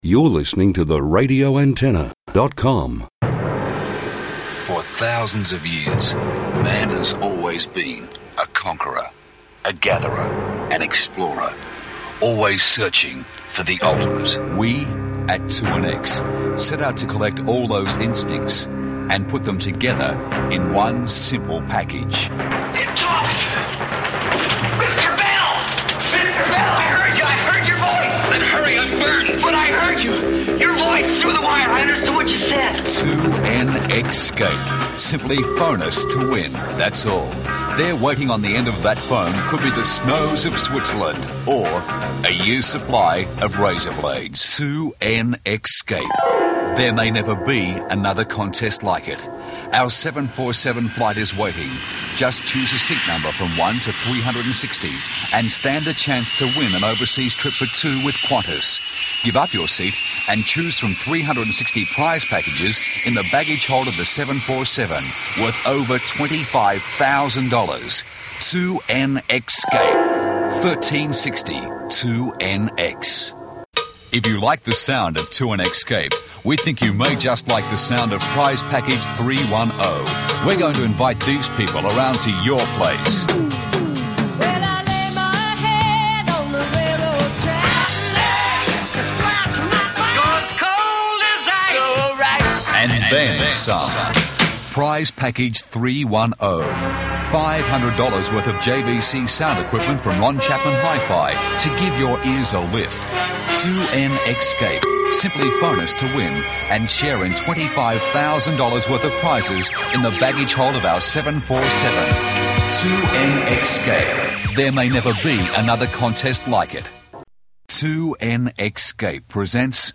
2NX-scape was one of three station promotions taking advantage of the X call sign.